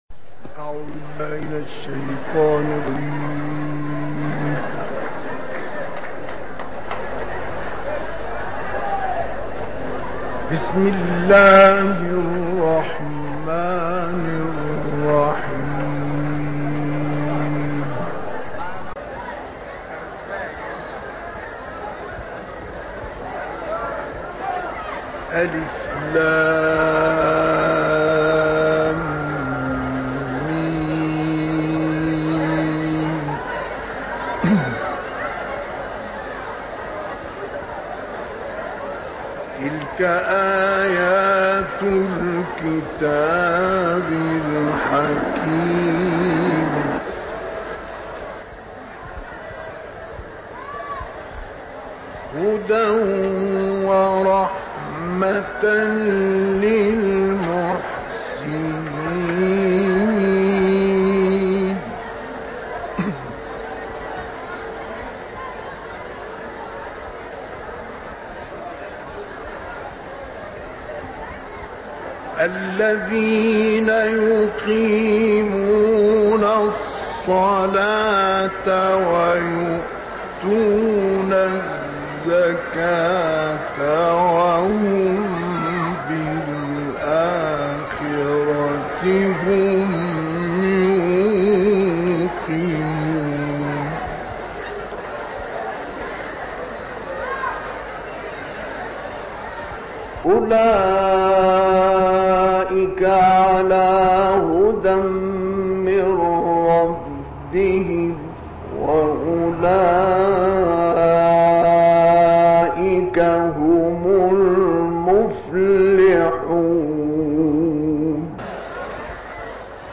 تلاوت «مصطفی اسماعیل» در مسجد ابوالعباس
گروه شبکه اجتماعی: تلاوت کمتر شنیده شده از مصطفی اسماعیل که در مسجد ابوالعباس شهر اسکندریه اجرا شده است، ارائه می‌شود.